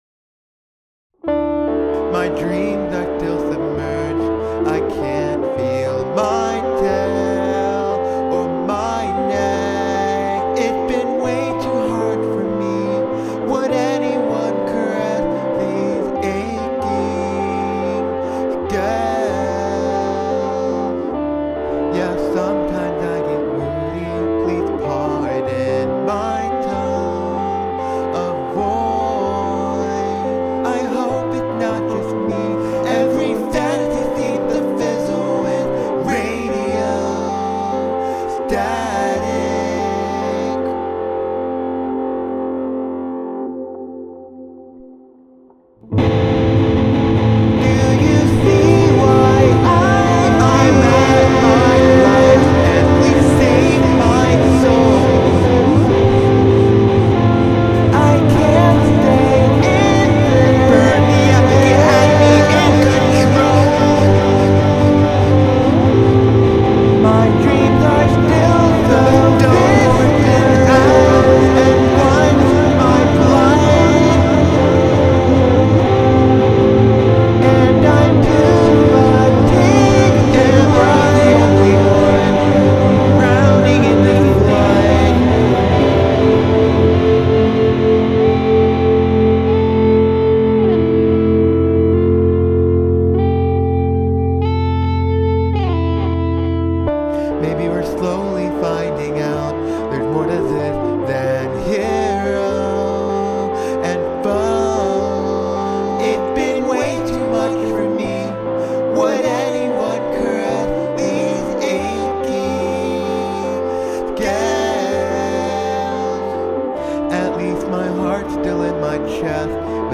Processing still sounds a little bit dirty I think, but that's what I get for having used the overdrive ...
Music / Rock
indie rock dragon song